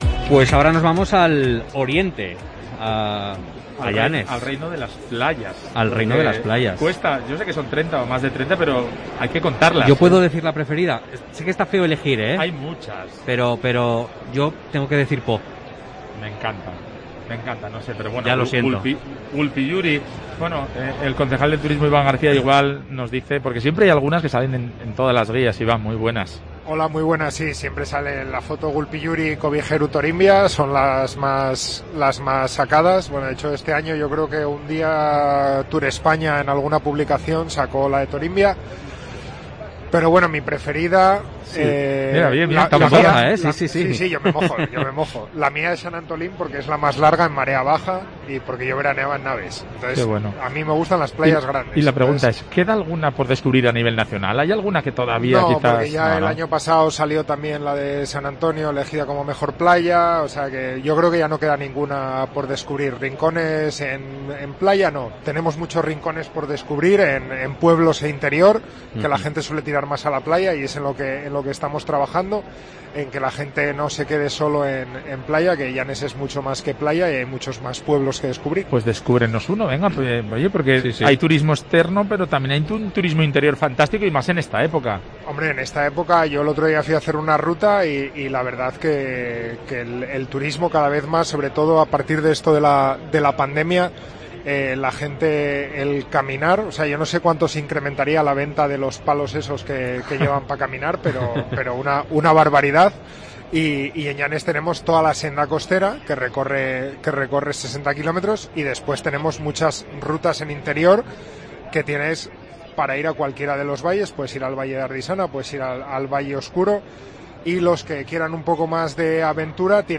El concejal de Turismo del municipio oriental asturiano, Iván García, ha estado en el programa especial de COPE Asturias desde Fitur
Fitur 2022: Entrevista a Iván García, concejal de Turismo de Llanes